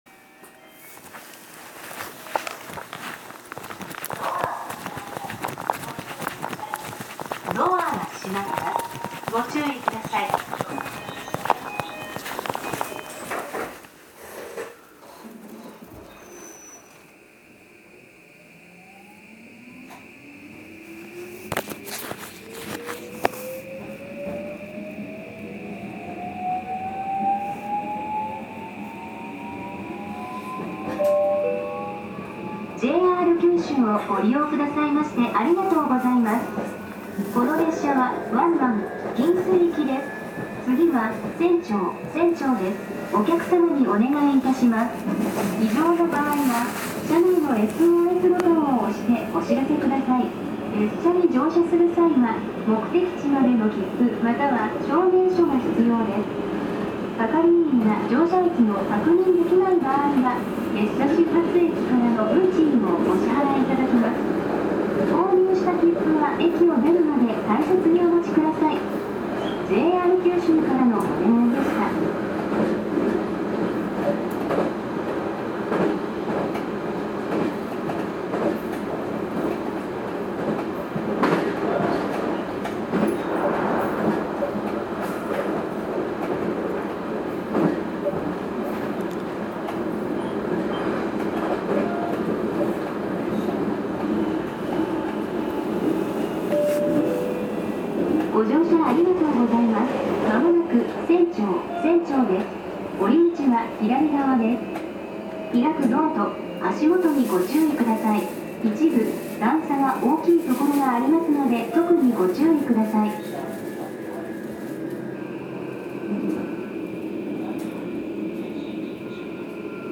制御装置は三菱電機製のフルSiC-MOSFETによるVVVFインバータとなってり、全閉自冷式かご形三相誘導電動機のMT406Kを採用しています。
走行音
録音区間：新八代～千丁(お持ち帰り)